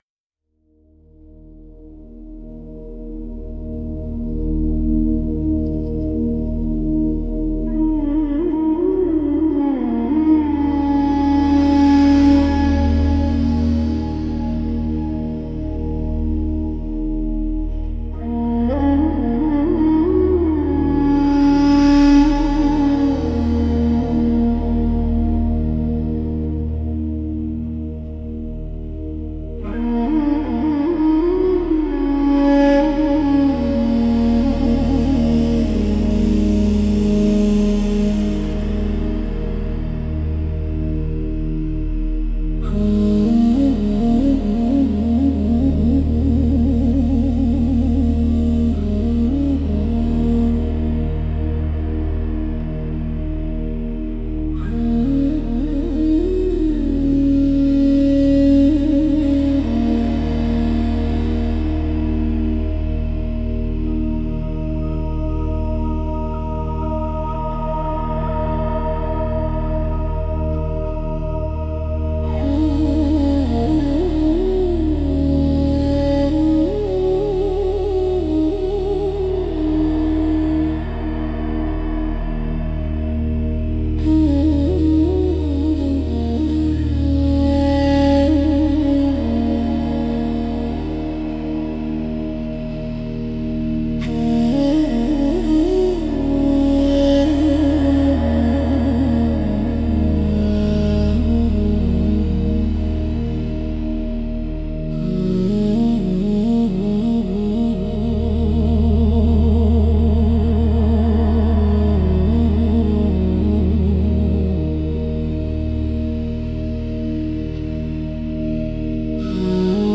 Kategorie: Yoga Musik / Yoga Flow Musik
Eigenschaften: instrumental, ohne Gesang, GEMA-frei